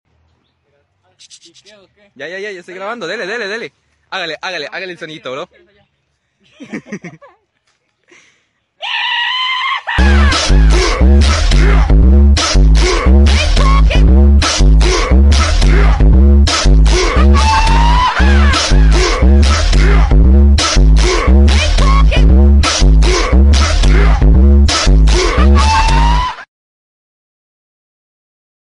Bungee zombie like voice of sound effects free download